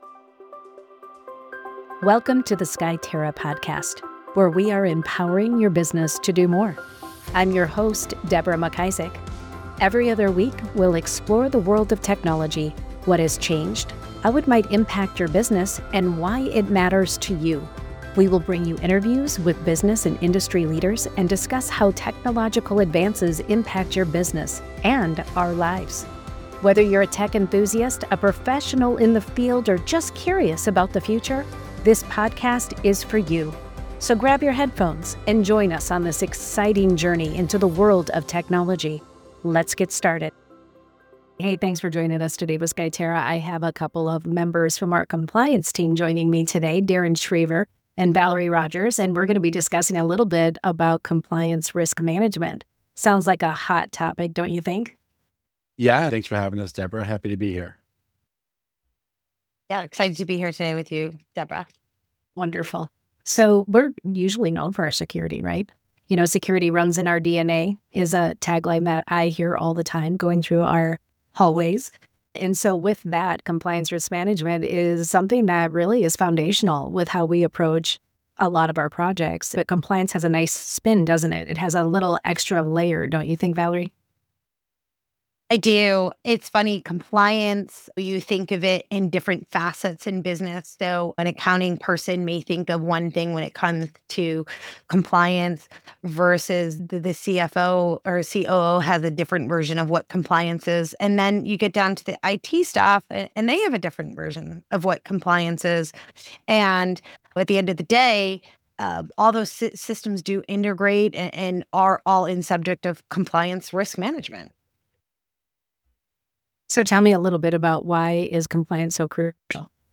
discussions with IT and Business Professionals facing the challenges of navigating an ever changing IT environment.